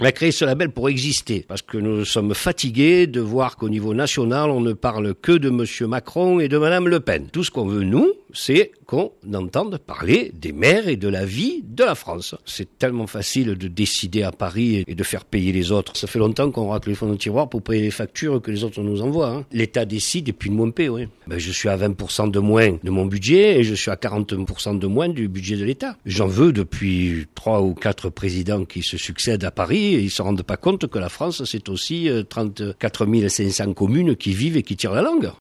À l’origine de ce label des "Maires de Provence", il y a Georges Cristiani, le maire de Mimet, qui compte 5.000 habitants et déplore des dotations de l’État amputées de 40%.